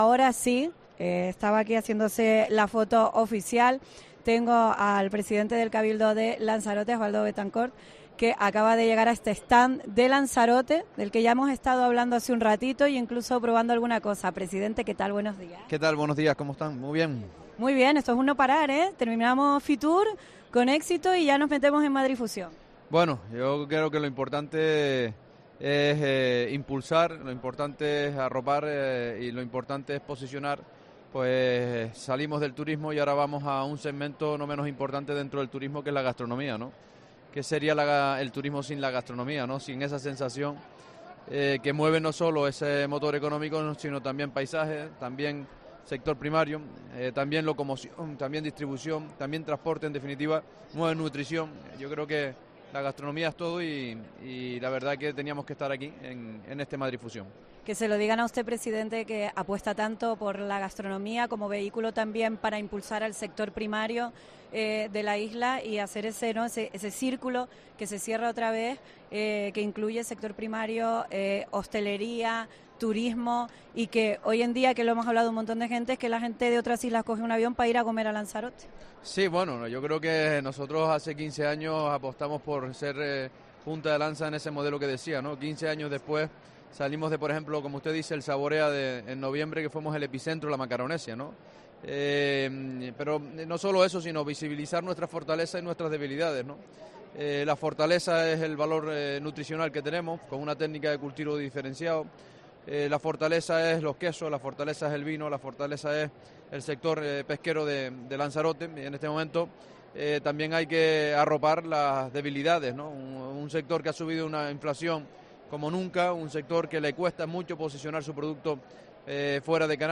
Entrevista
en Madrid Fusión